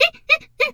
hyena_laugh_short_04.wav